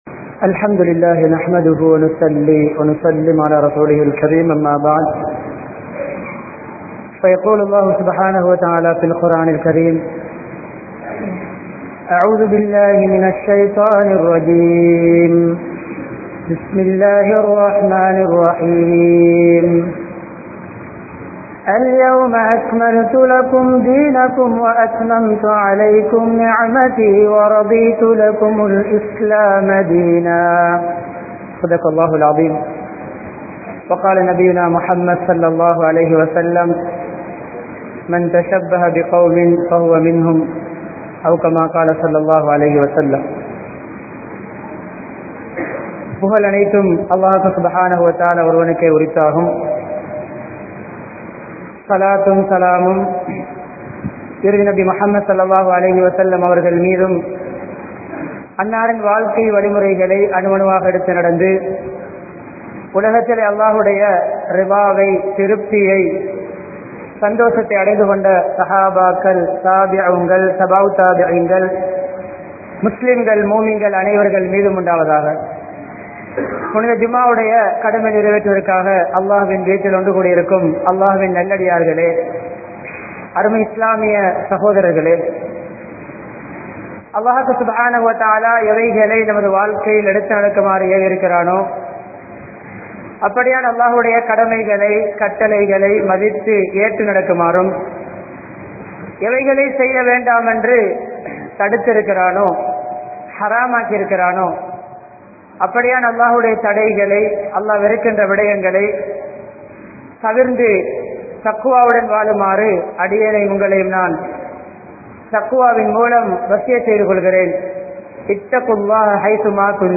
Islaaththin Paarvaiel Kaathalar Thinam (இஸ்லாத்தின் பார்வையில் காதலர் தினம்) | Audio Bayans | All Ceylon Muslim Youth Community | Addalaichenai